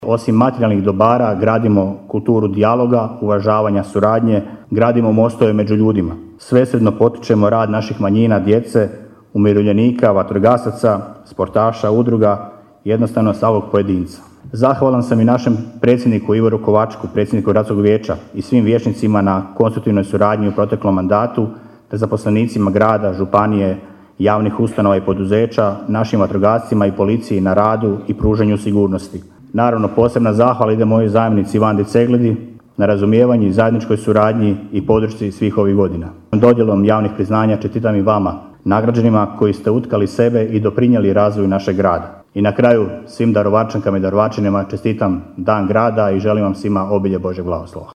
Svečana sjednica u povodu Dana Grada Daruvara održana je u dvorani Gradskog kina Pučkog otvorenog učilišta.